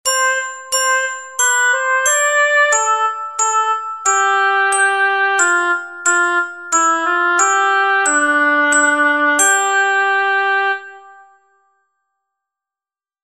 Pomp_and_Circumstance_binaria.mp3